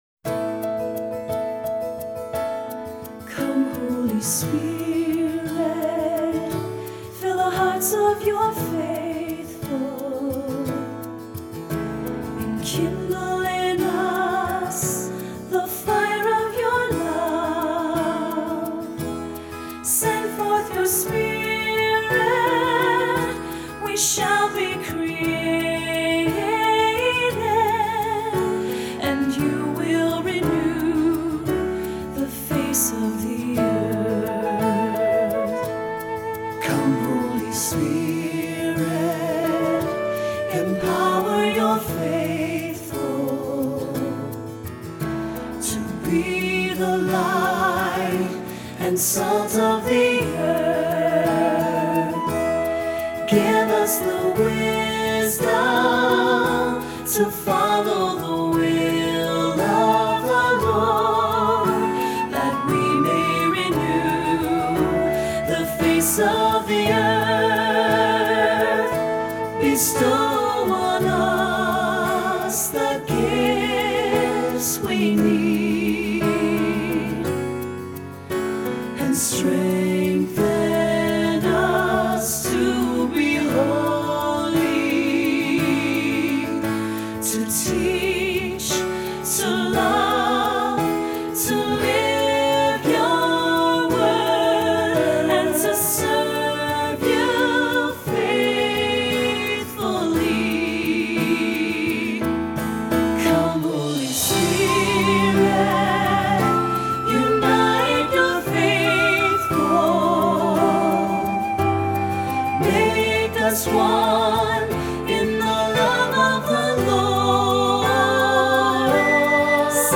Voicing: Two-part choir; cantor; assembly